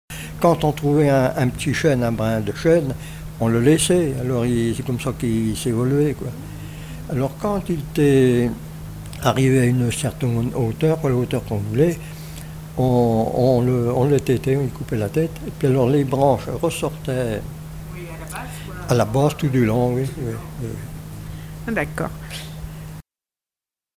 Témoignages de vie
Catégorie Témoignage